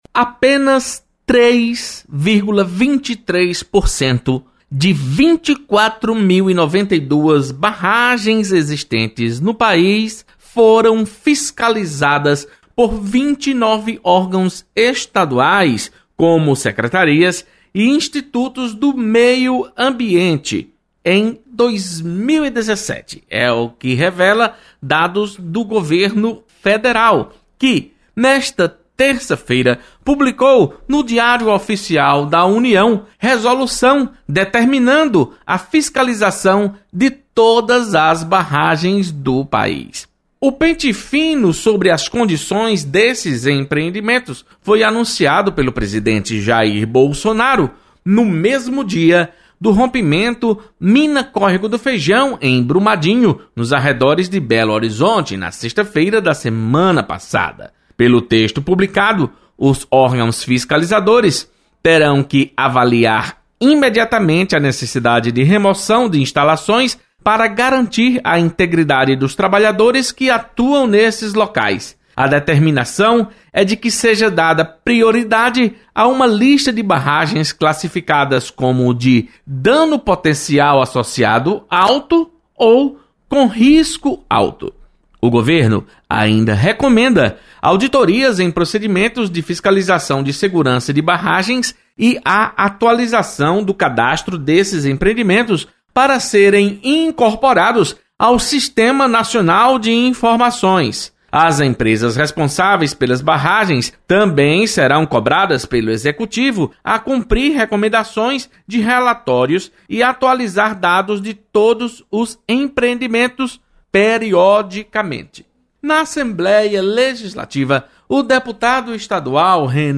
Deputado Renato Roseno Alerta para risco do desrespeito ao meio ambiente. Repórter